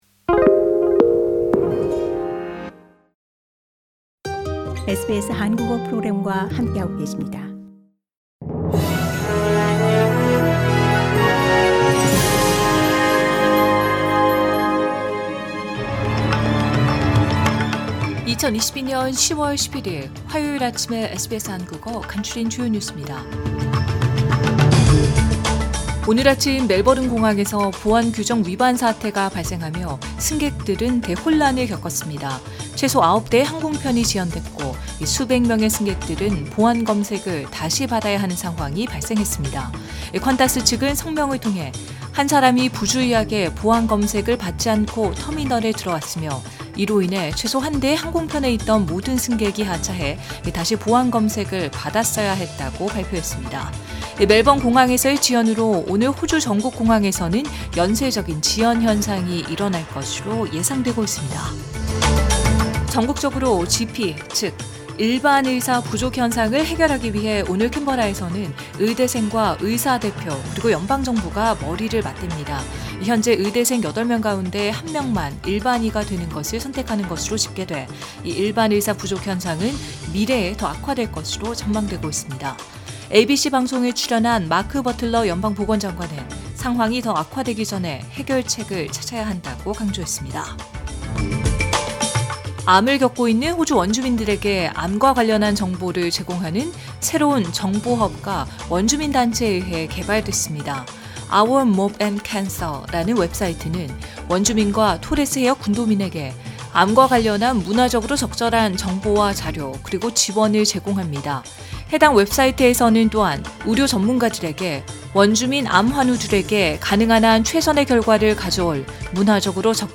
SBS 한국어 아침 뉴스: 2022년 10월 11일 화요일
2022년 10월 11일 화요일 아침 SBS 한국어 간추린 주요 뉴스입니다.